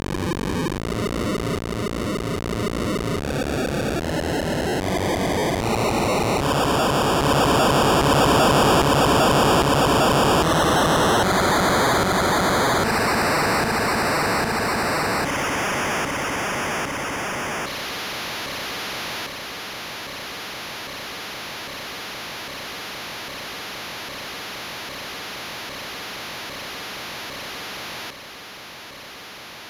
rocket_ending.wav